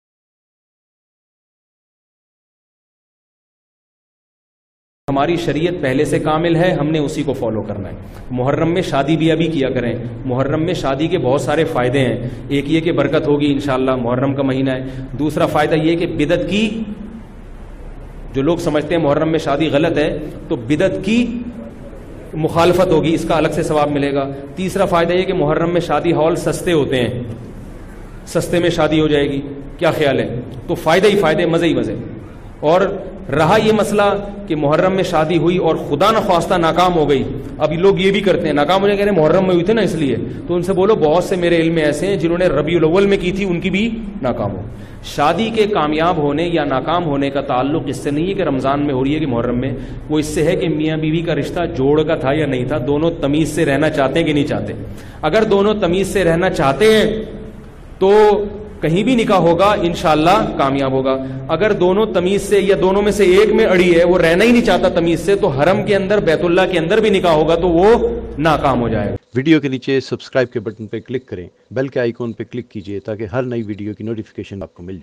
Muharram mein Shadi ka Hukm Kya bayan mp3